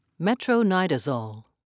(me-troe-ni'da-zole)